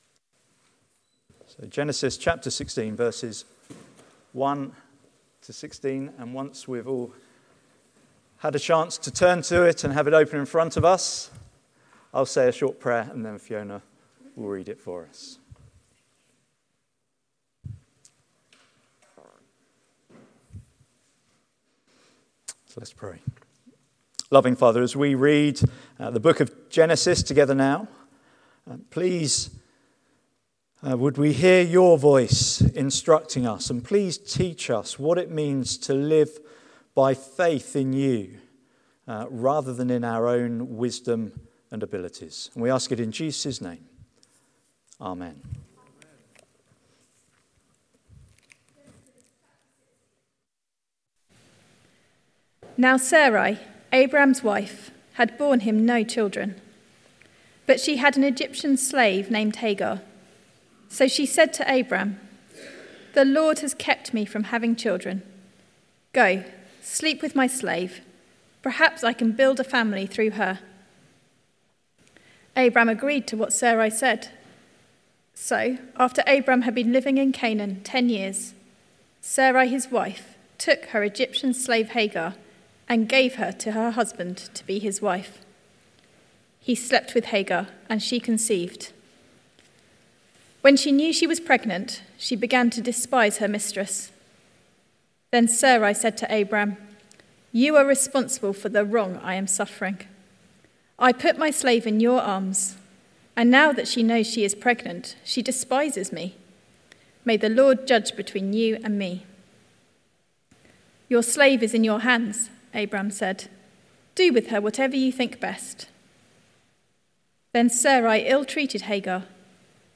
Sermon Transcript